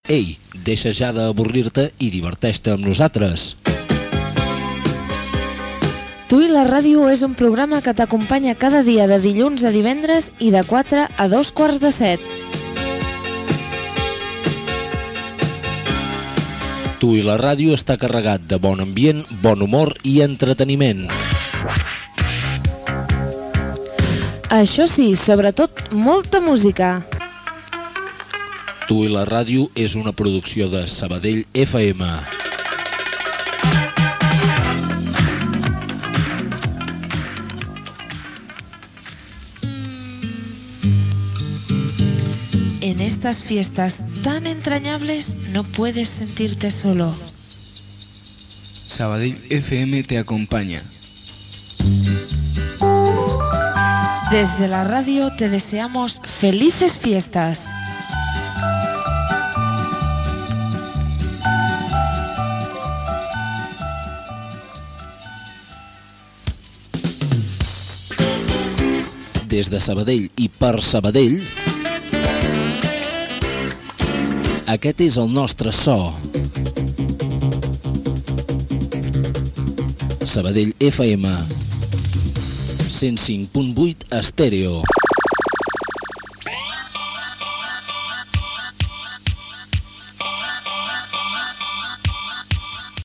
Promoció del programa, felicitació de Nadal, indicatiu de l'emissora, als 105.8 FM